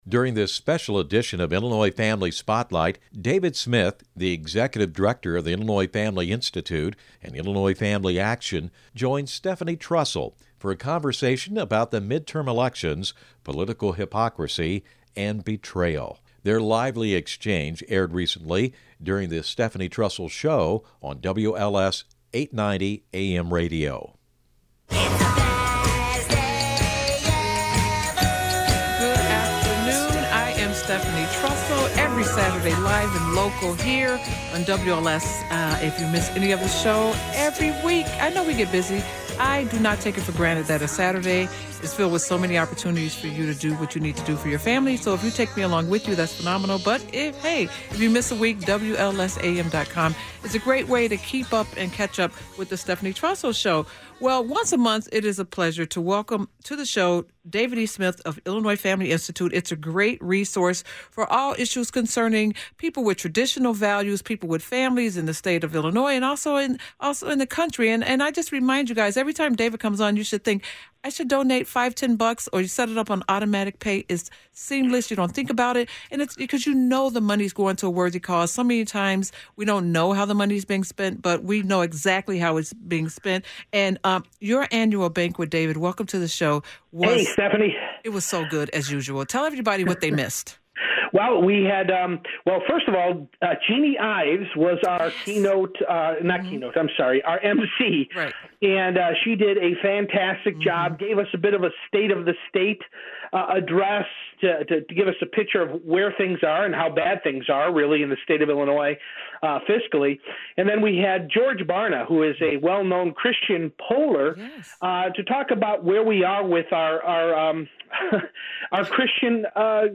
Christian conservative talk radio